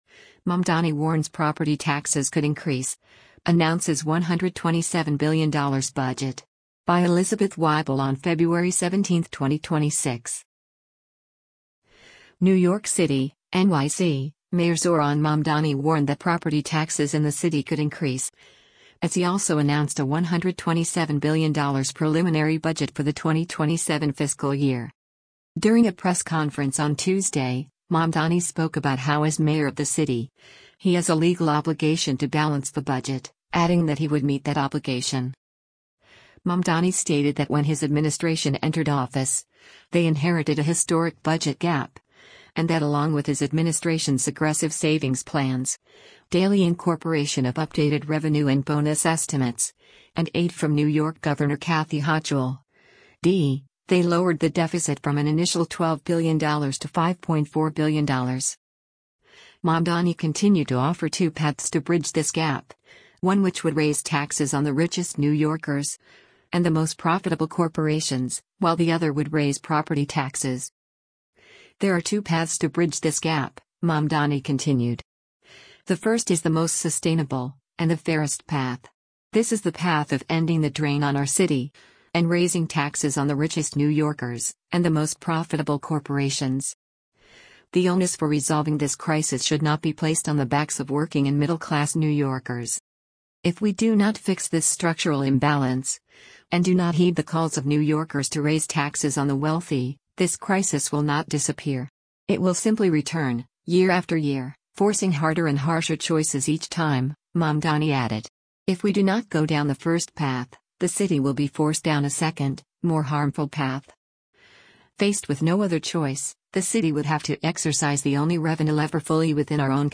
During a press conference on Tuesday, Mamdani spoke about how as mayor of the city, he has a “legal obligation to balance the budget,” adding that he would “meet that obligation.”